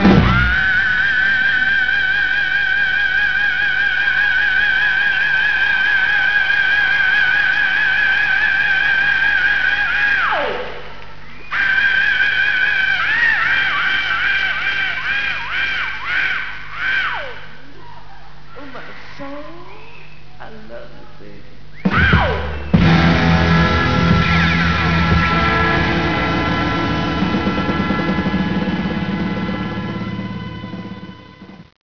schrei.wav